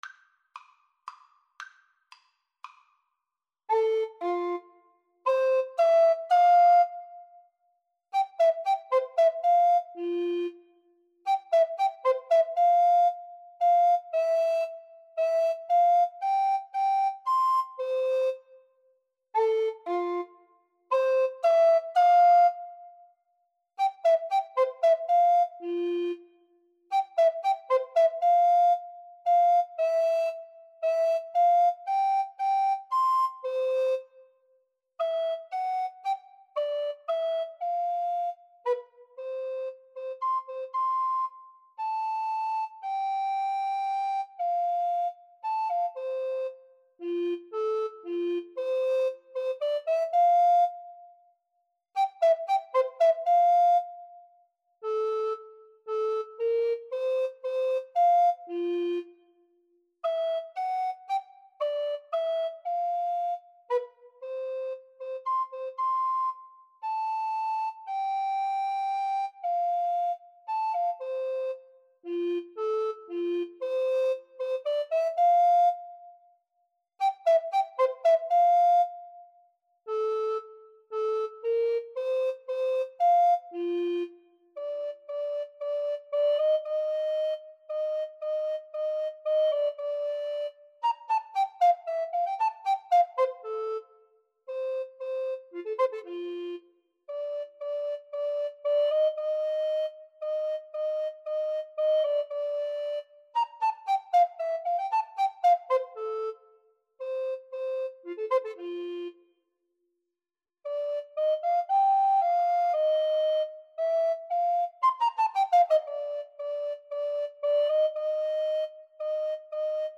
Free Sheet music for Recorder Duet
F major (Sounding Pitch) (View more F major Music for Recorder Duet )
3/4 (View more 3/4 Music)